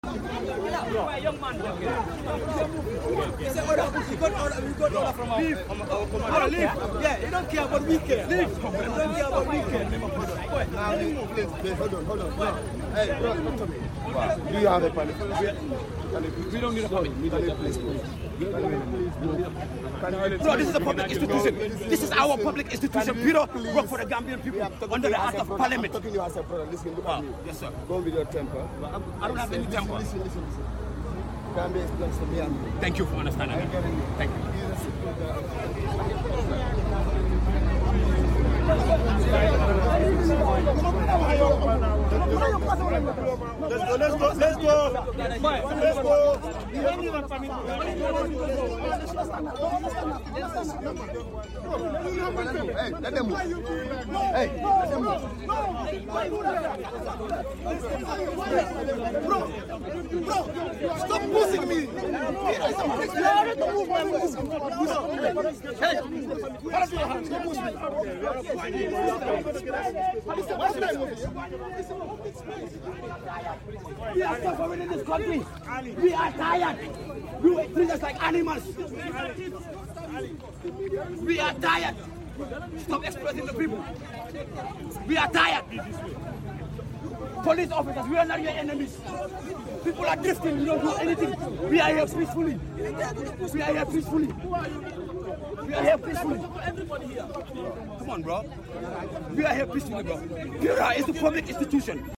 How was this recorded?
Scene from the PURA protest sound effects free download